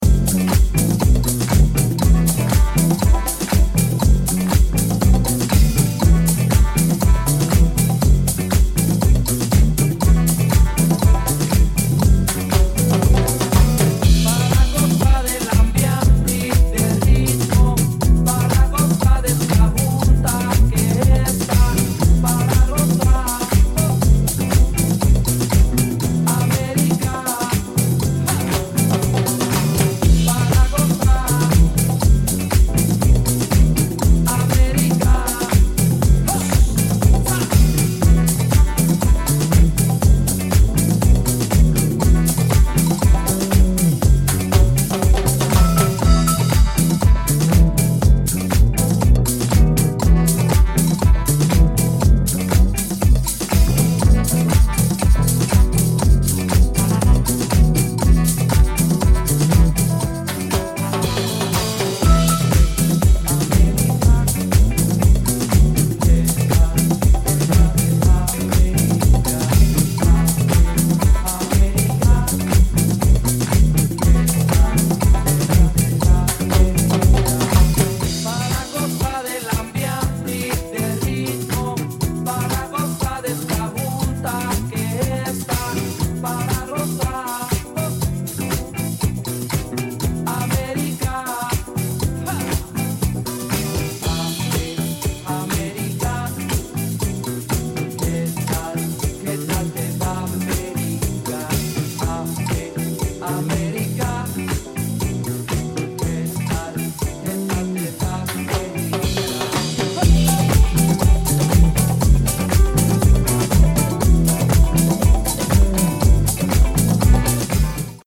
Disco / Balearic Edit